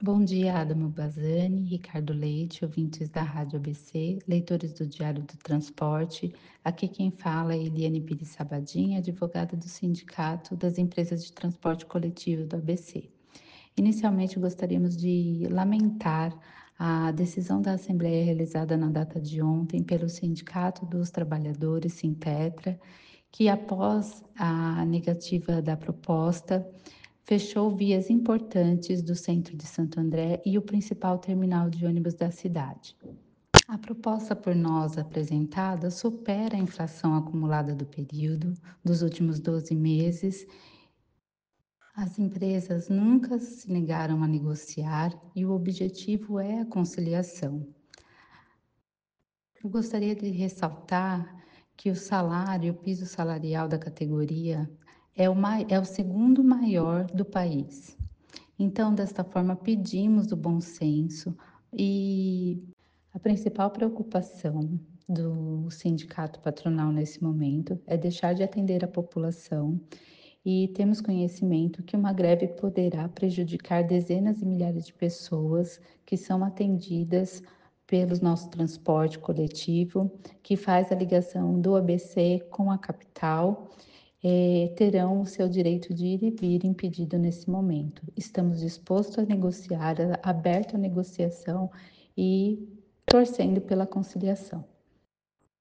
SONORA-ADVOGADA.mp3